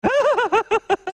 Mad Hatter laughter 2 file size 0.02 MB download count 620 [view_count] views categories Soundclips Watch Download